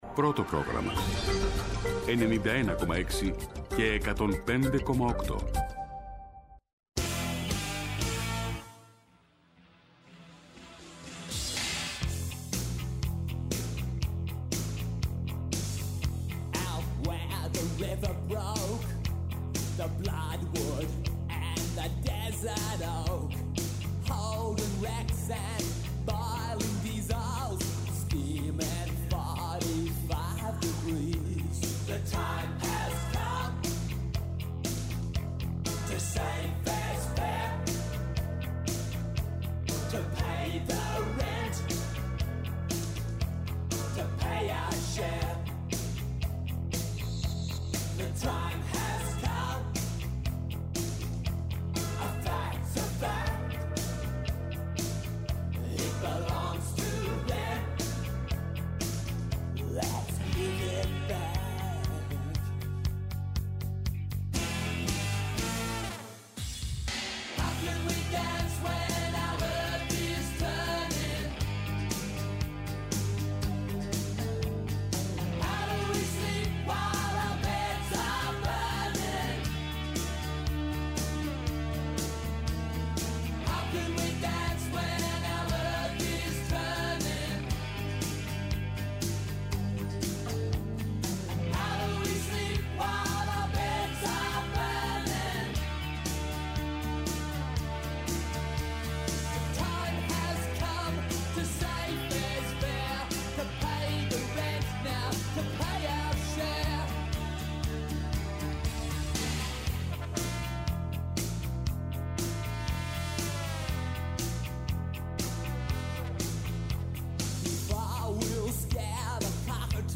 Ο απόηχος της εγχώριας και διεθνούς πολιτιστικής ειδησεογραφίας με στόχο την ενημέρωση, τη συμμετοχή, και τελικά την ακρόαση και διάδραση. Ένα ραδιοφωνικό «βήμα» σε δημιουργούς που τολμούν, αναδεικνύουν την δουλειά τους, προτείνουν και αποτρέπουν.